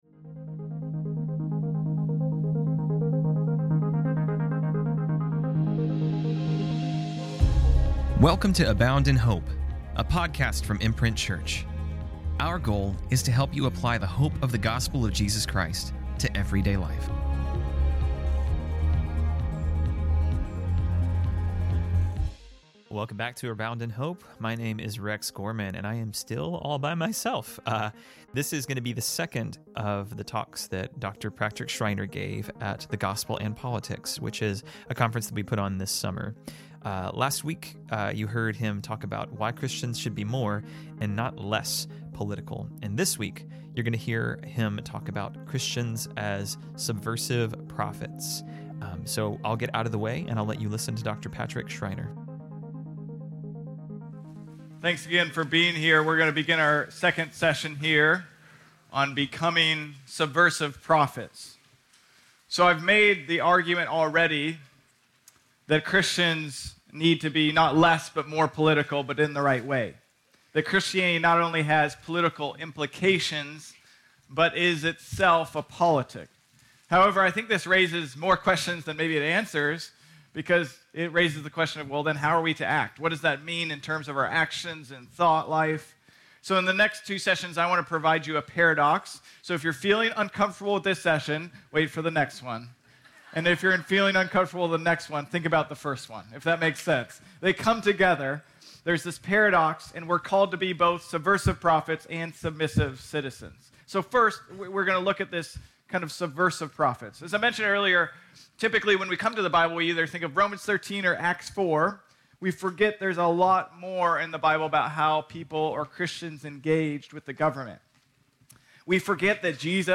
This week, we are airing the second session from The Gospel& Politics, a conference we put on in Summer of 2024.